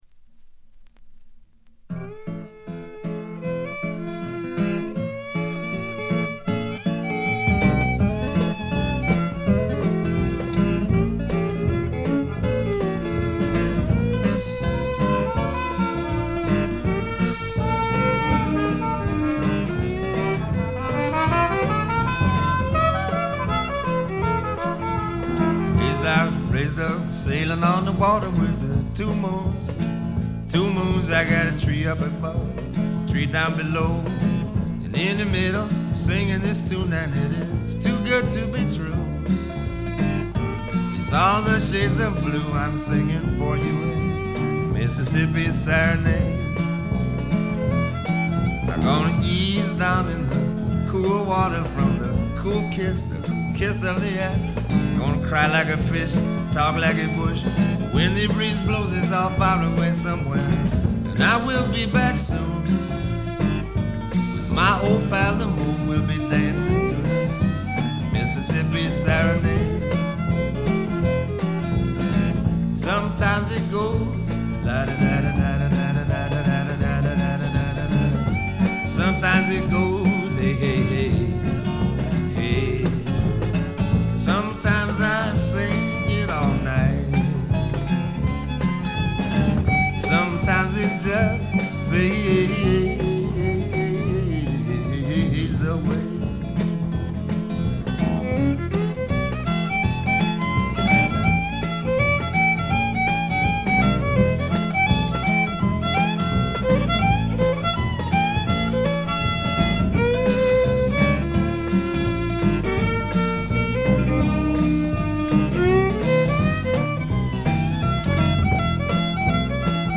にがみばしった男の哀愁を漂わせたヴォーカルがまず二重丸。
試聴曲は現品からの取り込み音源です。
Guitar, Vocals
Drums
Trumpet